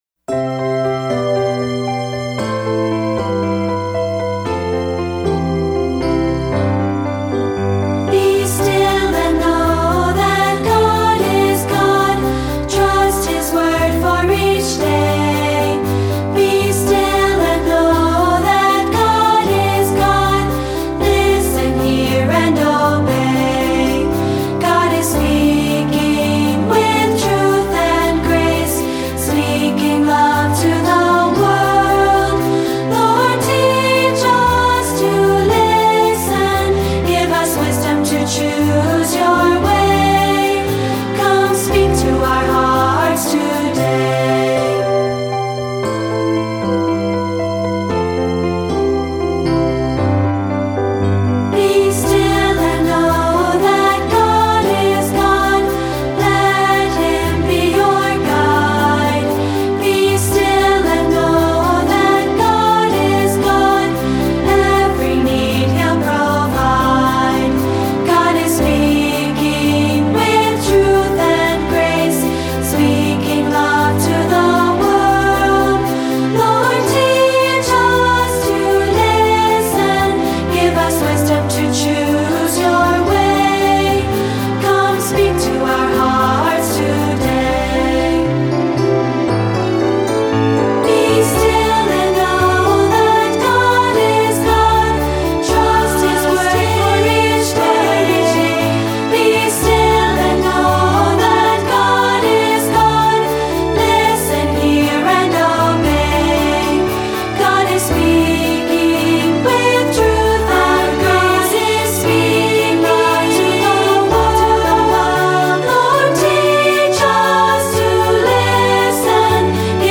Voicing: Unison/2-Part